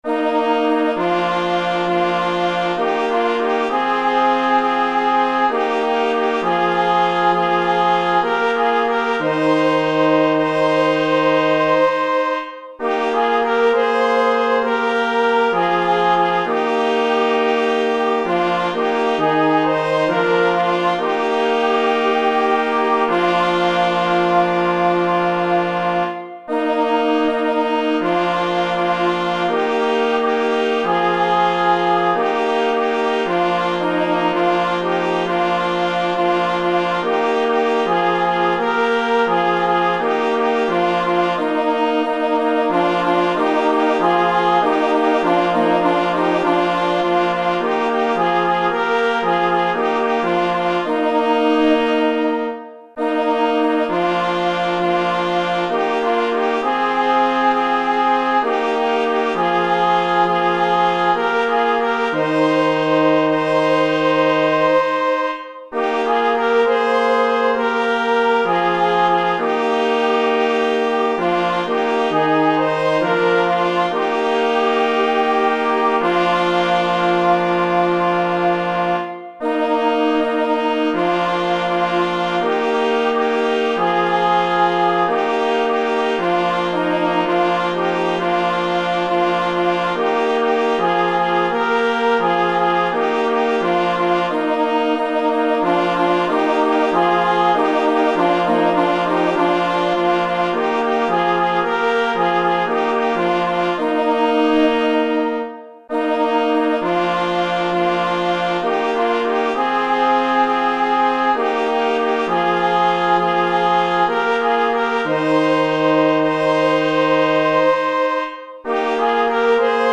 pastorałka myśliwska – śpiew z tow. zespołu